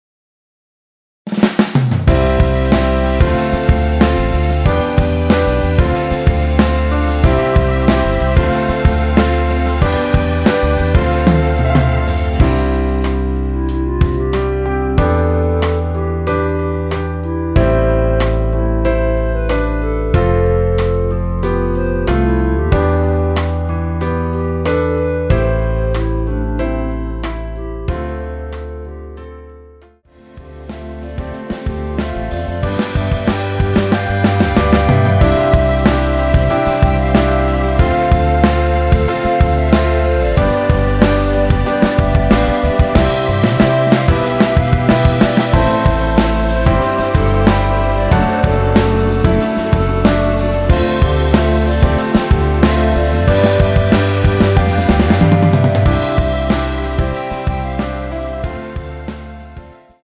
원키 멜로디 포함된 MR입니다.(미리듣기 확인)
앞부분30초, 뒷부분30초씩 편집해서 올려 드리고 있습니다.
중간에 음이 끈어지고 다시 나오는 이유는